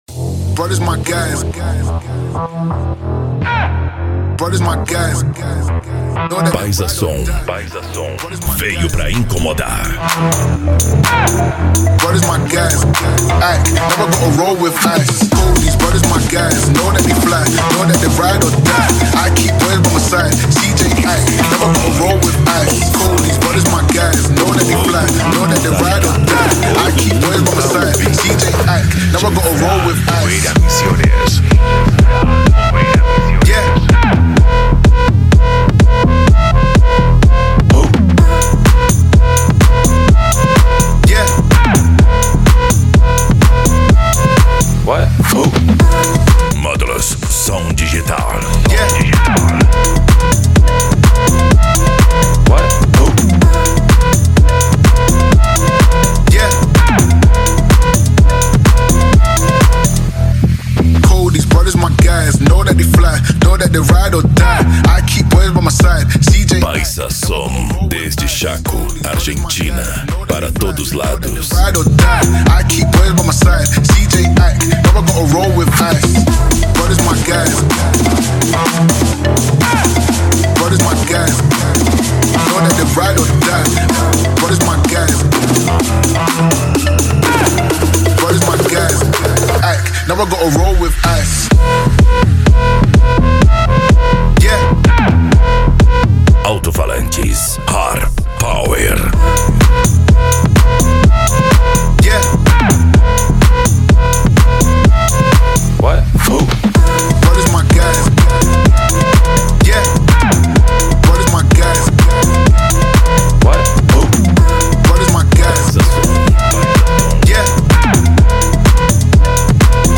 Psy Trance
Racha De Som
Remix